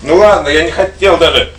Worms speechbanks
Traitor.wav